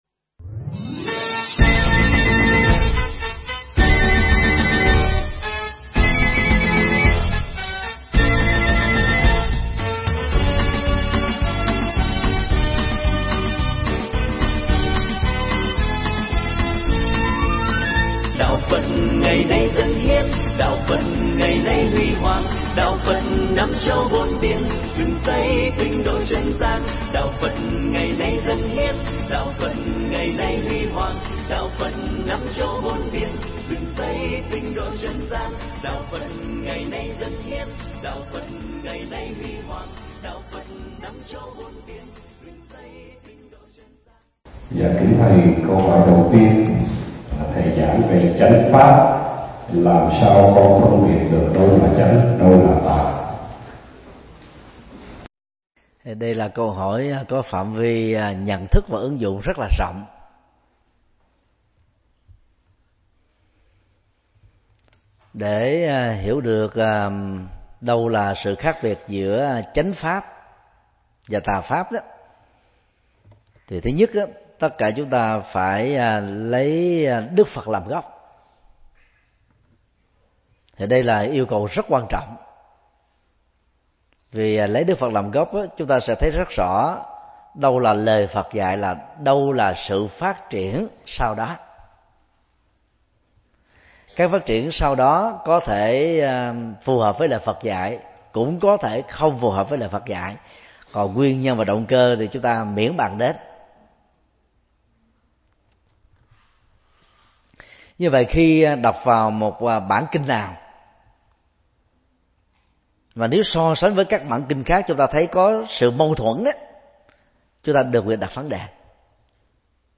Mp3 Vấn Đáp: Khó Hiểu Khi Tụng Kinh Hán Việt – Thượng Tọa Thích Nhật Từ giảng tại chùa West End, Canada, ngày 21 tháng 5 năm 2017